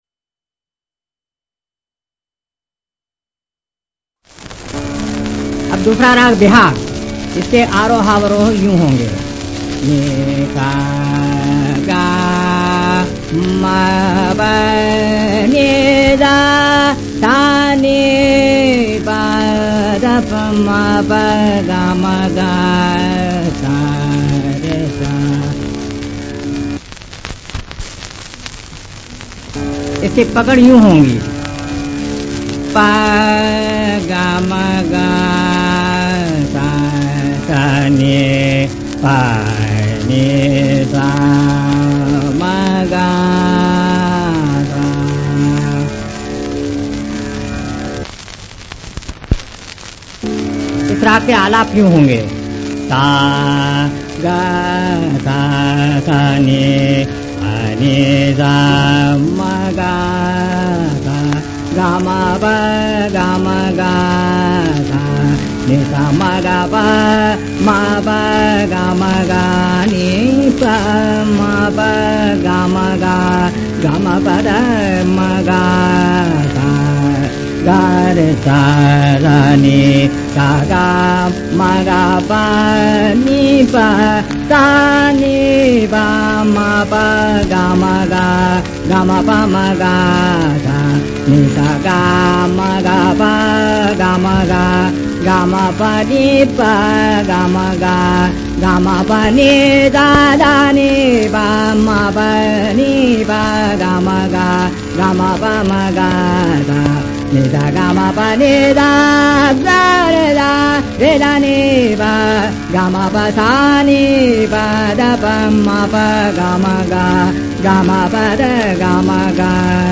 उनके कुछ ही ऑडियो क्लिप विनाइल डिस्क या रेडियो कार्यक्रमों में रिकॉर्ड किए गए हैं, और संभवतः 1940 या 1950 के दशक में वे रिकॉर्ड किए गए थे।
राग बिहाग- लेक्चर डेमोंस्ट्रेशन - श्रीनारातंजनकर -३ मि.